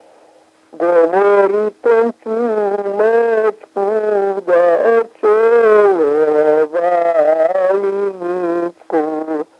Мягкое чоканье (совпадение литературных аффрикат /ц/ и /ч’/ в мягком /ч’/)
/у-то-го”-л’е чуу-маа-чка” уу-ц’оо-лоо-ваа”-л’и-н’и-чка/